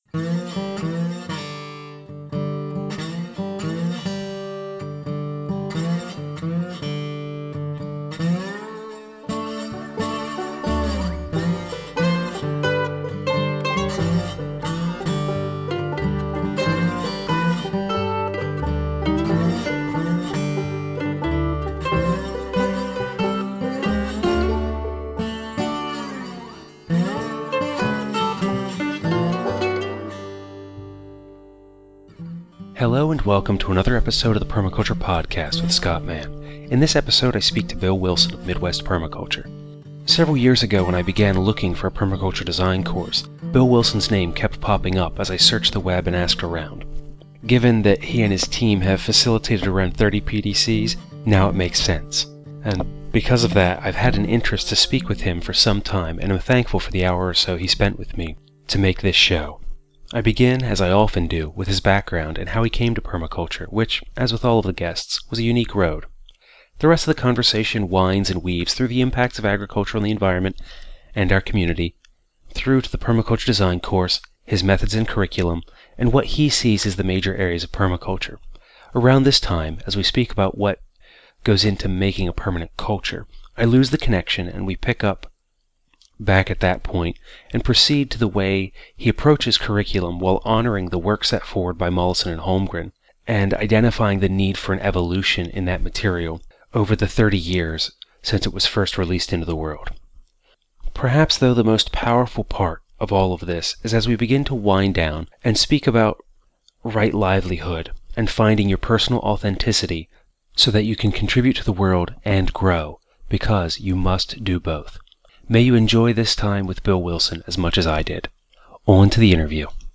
The rest of the conversation winds and weaves through the impacts of agriculture on the environment and our communities, to the Permaculture Design Course, his methods and curriculum, and what he sees as the major areas of permaculture education. Around this time, as we speak about what makes for a permanent culture, I lose the connection and we pick back up on that point, and proceed to the way he approaches curriculum while honoring the work set forward by Mollison and Holmgren and identify the evolution that is required 30 years after releasing this idea into the wild.